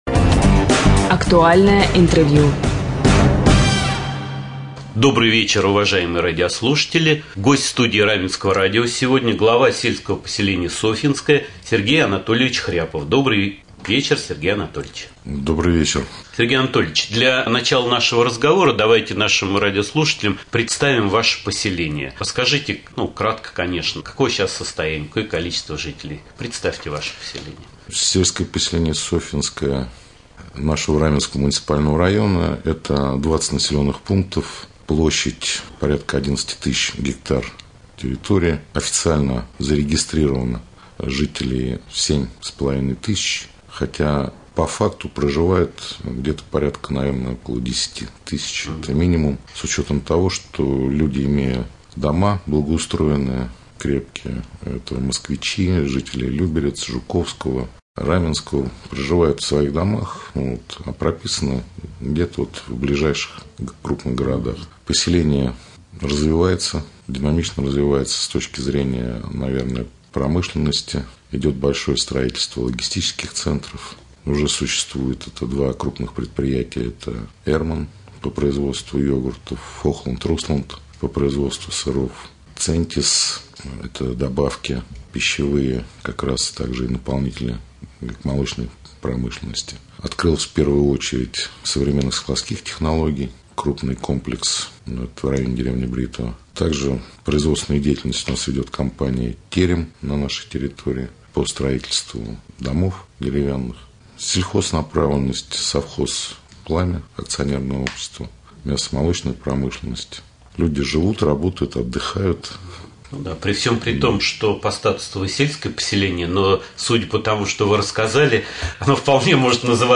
Герой рубрики «Актуальное интервью» глава с/п Софьинское Хряпов Сергей Анатольевич.